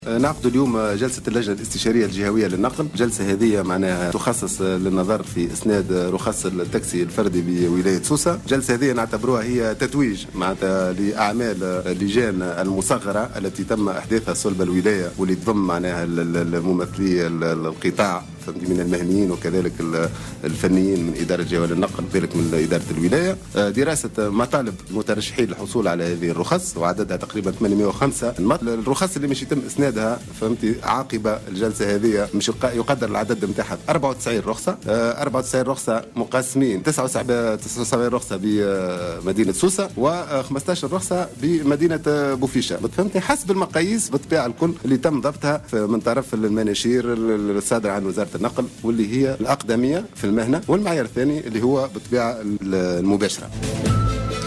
أكد كاتب عام ولاية سوسة، منذر العريبي اليوم الجمعة في تصريح ل"جوهرة أف أم" أنه تقرّر إسناد 94 رخصة تاكسي فردي من جملة 805 مطلب.